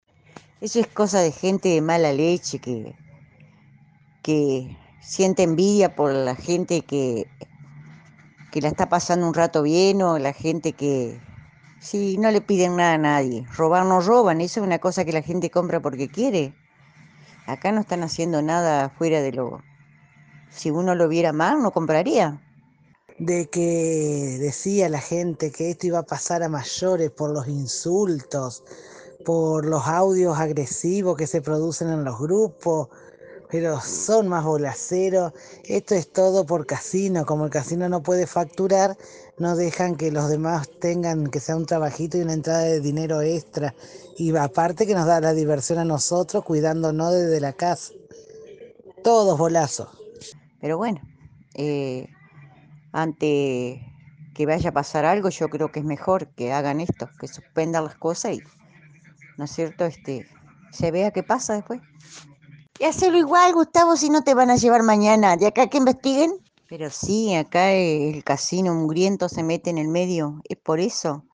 Reacciones de oyentes en AM 980